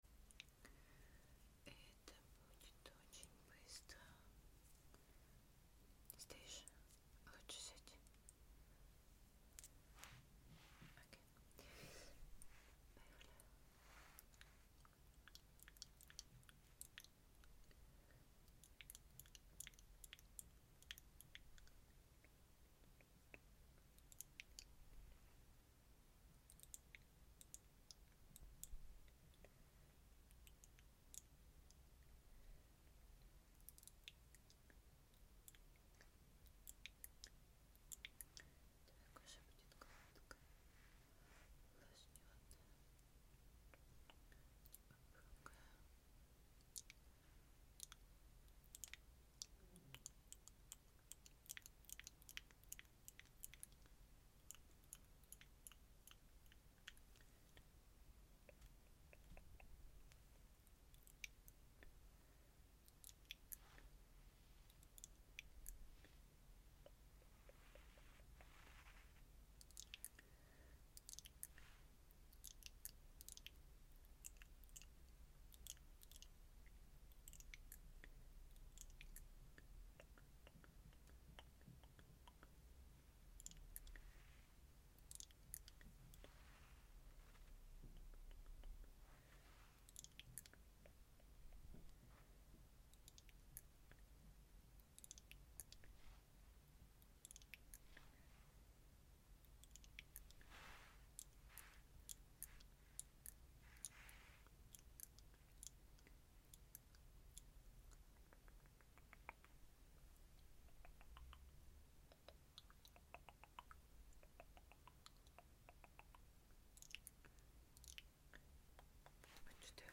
Asmr massage with relaxation cream sound effects free download